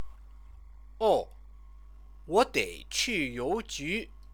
Comments Comments  Alternatively: 哦 , 我 要 去 邮 局; or 哦, 我 得 去 一 下 邮 局. 哦 in fourth tone, is used to indicate that one has suddenly realised something, 哦 , 你 的 车 在 我 这 儿 Oh, your bike is at my place.